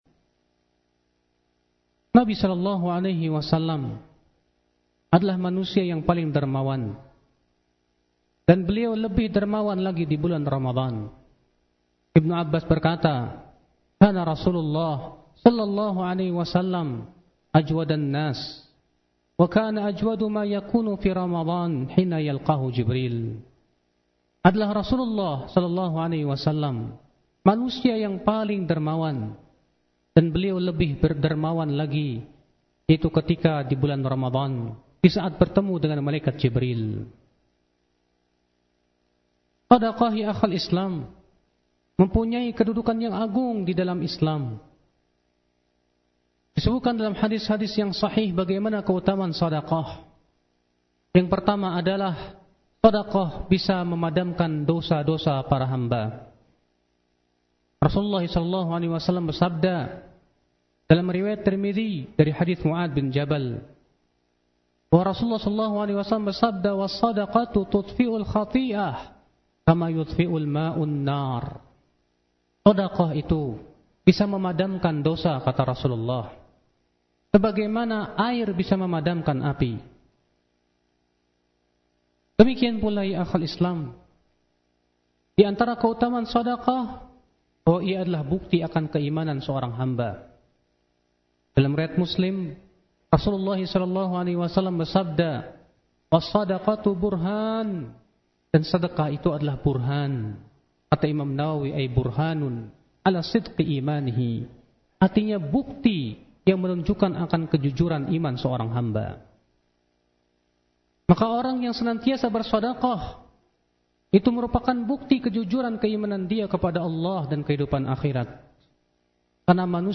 حفظه الله تعالى   berikut ini dalam khutbah Jum’at di Masjid Al Barkah pada tanggal 26 Juni 2015 atau bertepatan dengan 9 Ramadhan 1436 H.